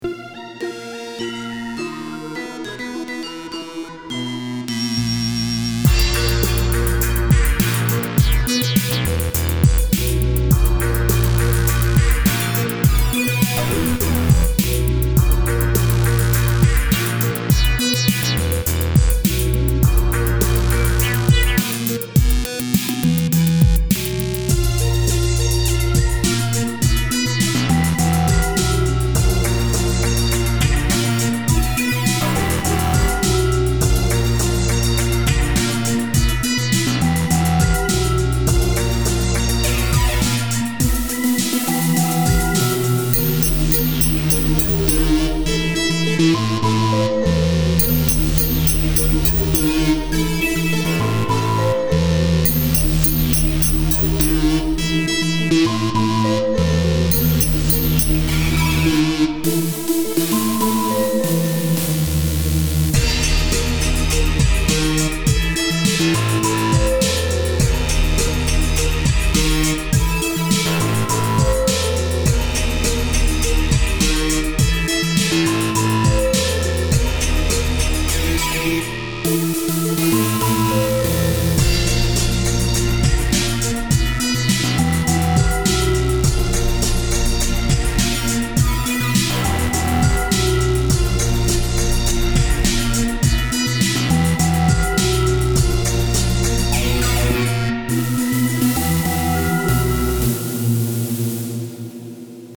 Jam for some a funky zone, like a sewer lair.
sewer_beat_2026_loop_0.mp3